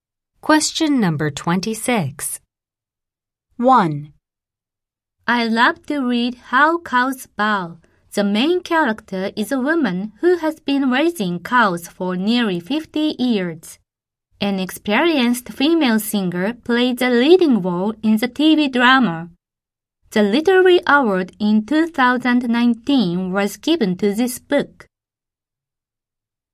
音声は1回読みや、イギリス英語、英語非母語話者の英語の聞き取りにも対応しています。